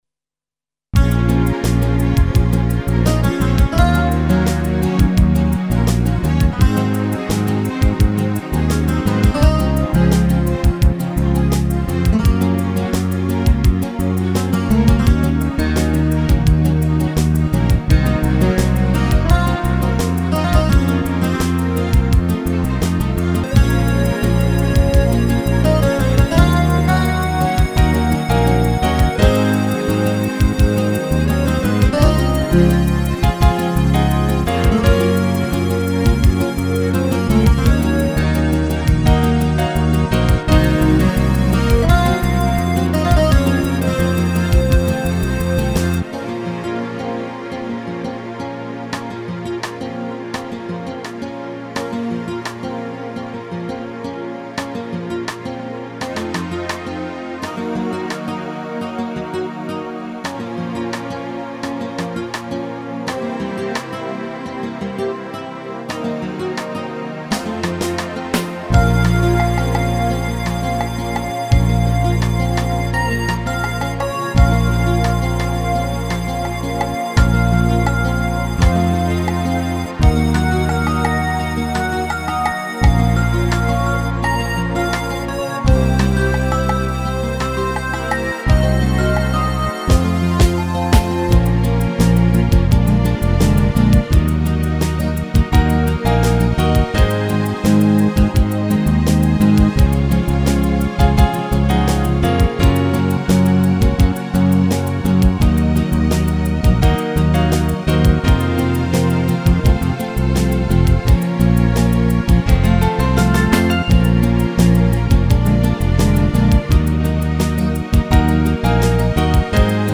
караоке